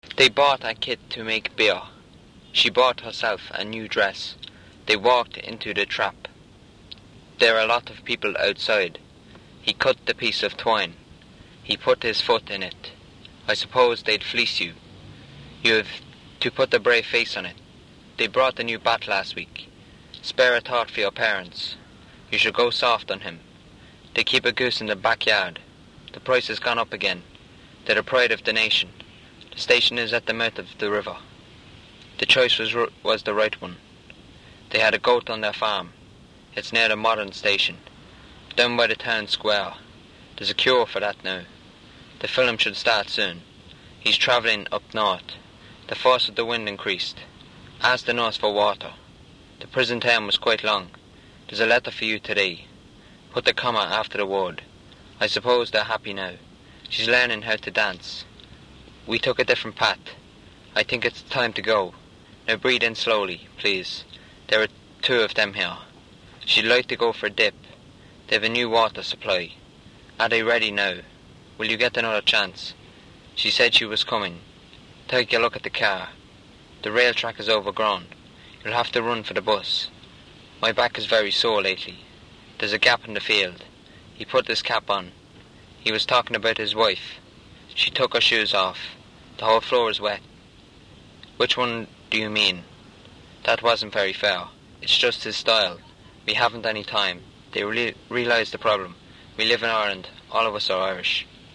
Young speaker with uvular R (north Leinster dialect area)
LOU_Drogheda_U2_M_18.wav